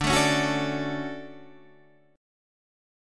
DM7 chord